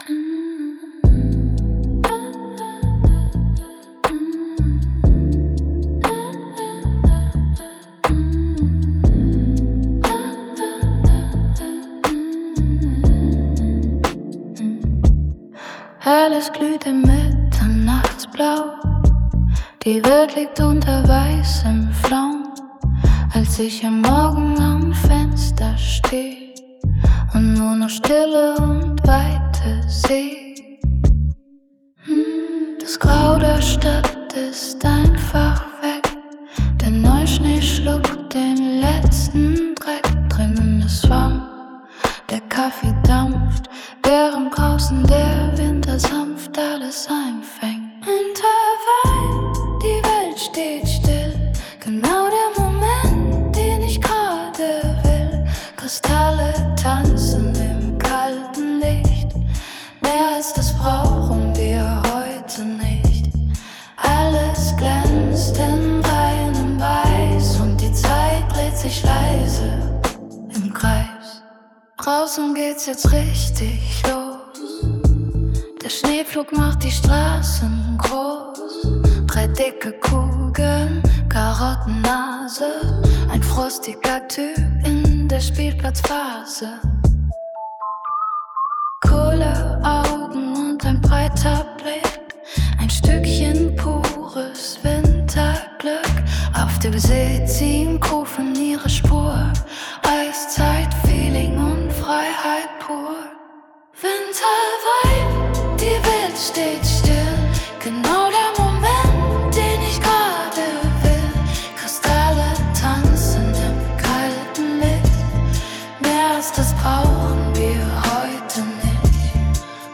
Song 1: Sängerin, Dream Pop, Synthesizer, Chime, Celeste, Soft 808-Drums